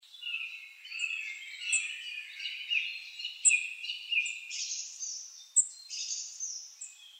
Larghetto [60-70] amour - autre - oiseau - nature - detente